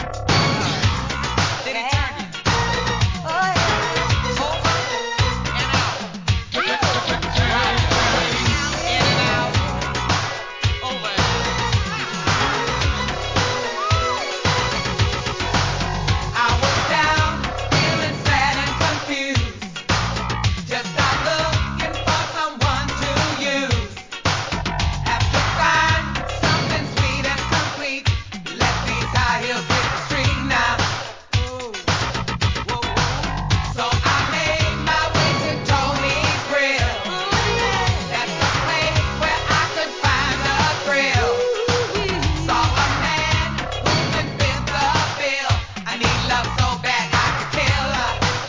NEW JACK SWING!!